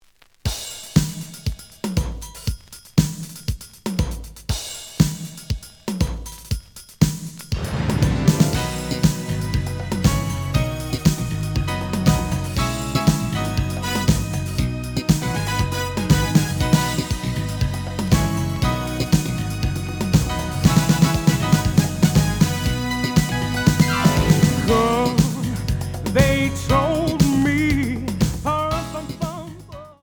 The audio sample is recorded from the actual item.
●Genre: Rhythm And Blues / Rock 'n' Roll
Slight noise on beginning of A side, but almost good.)